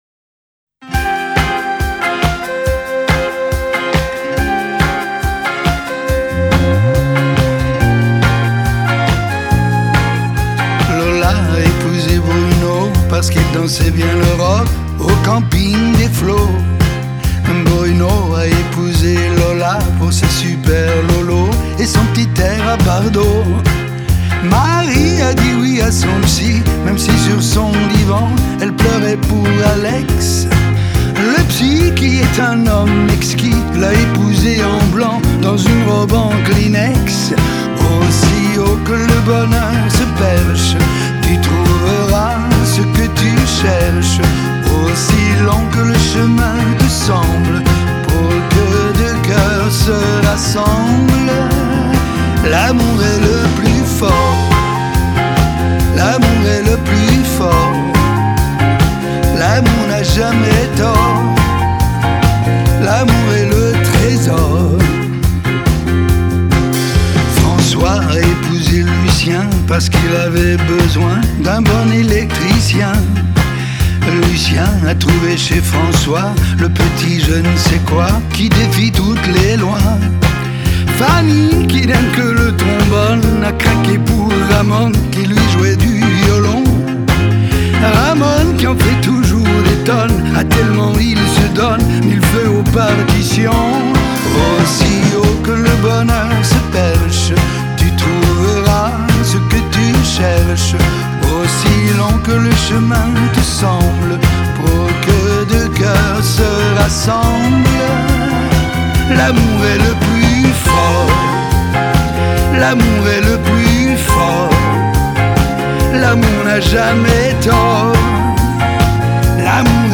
Genre: French Pop, French Chanson